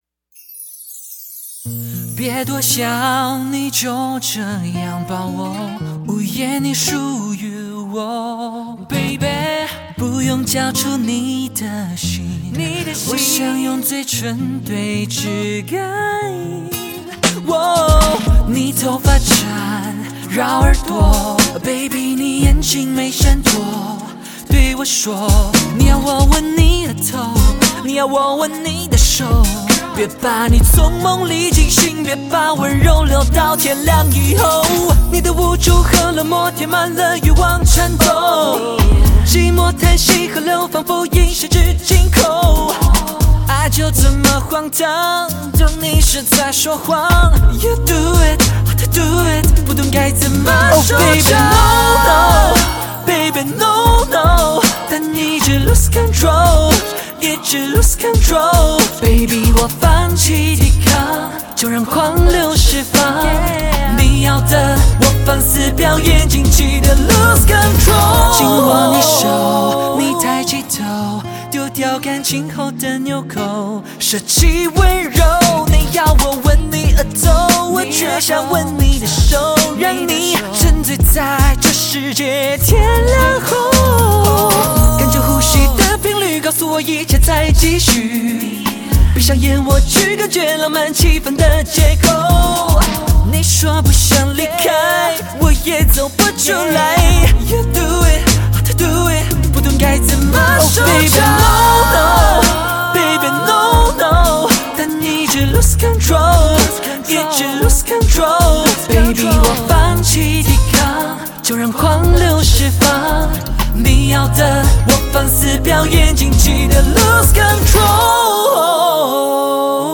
温柔的歌声让歌曲的氛围更显浓厚